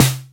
Retro Snr 2.wav